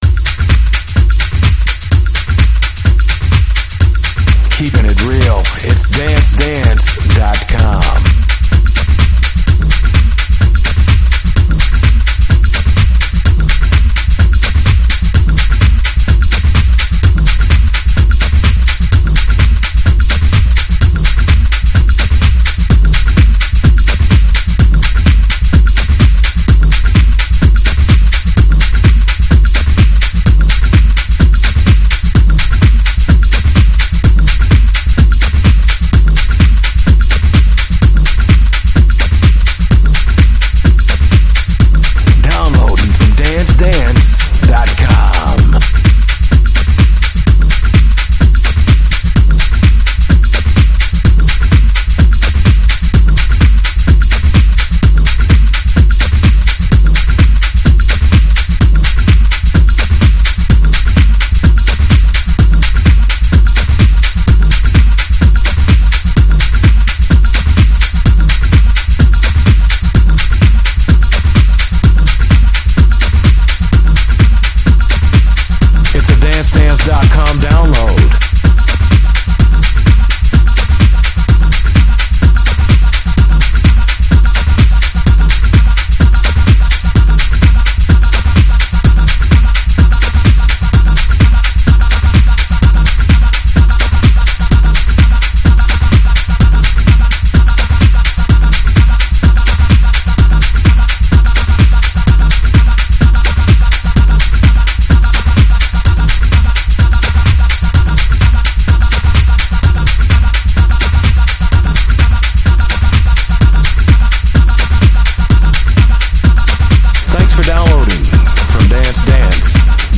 new dj tool for your techno or house set